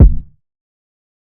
TC2 Kicks6.wav